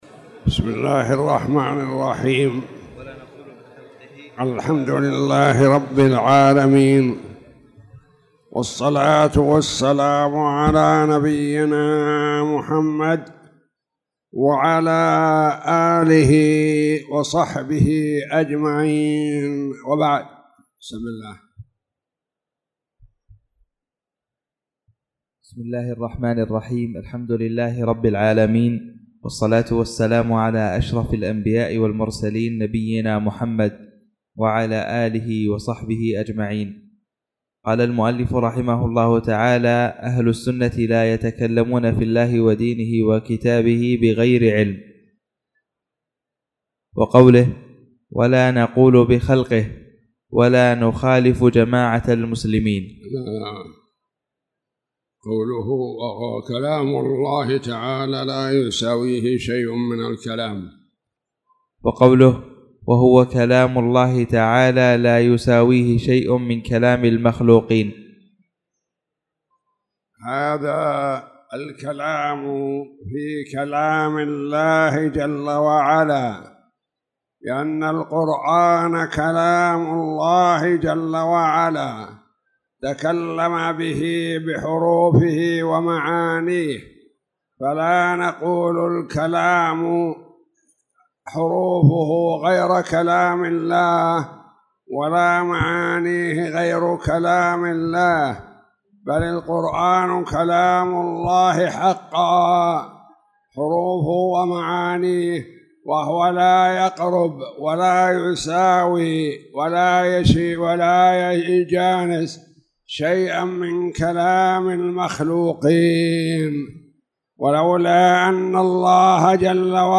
تاريخ النشر ١٦ شعبان ١٤٣٧ هـ المكان: المسجد الحرام الشيخ